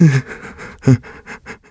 Riheet-Vox_Sad.wav